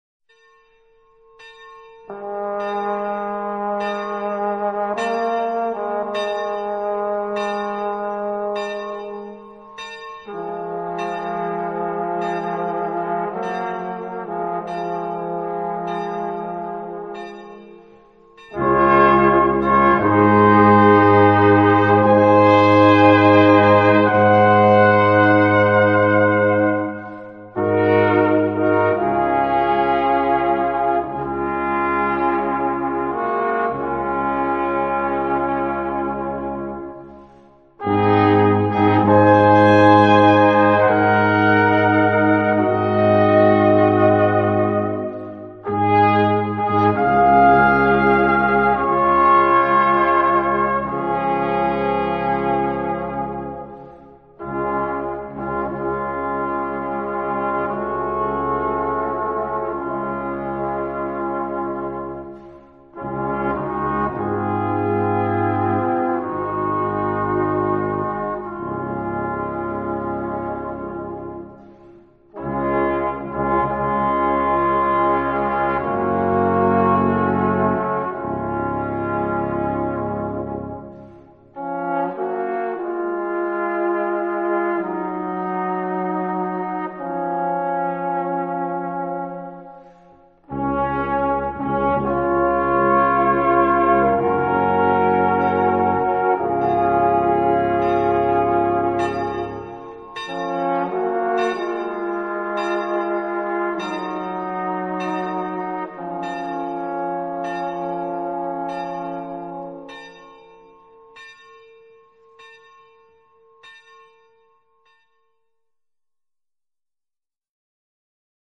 Besetzung: Ensemblemusik für 4 Blechbläser
1./2. Flügelhorn - Posaune - Tuba.